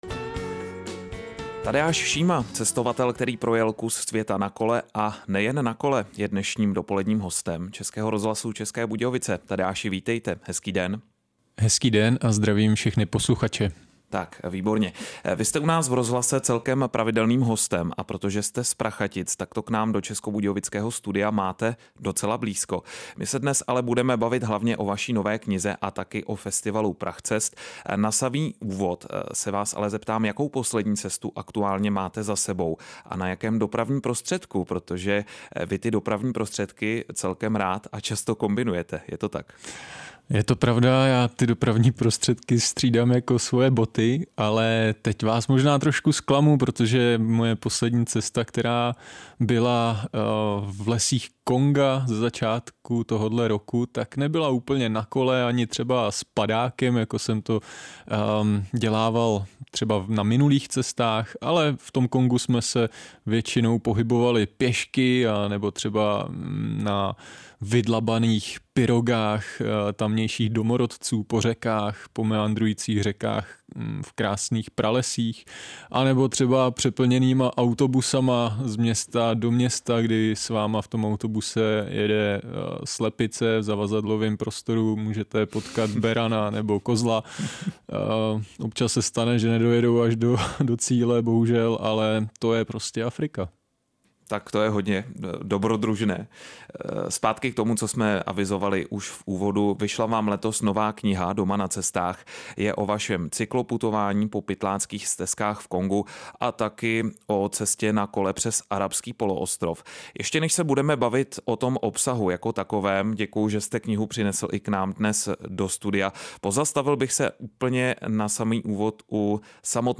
Reportáže z jižních Čech, písničky na přání a dechovka.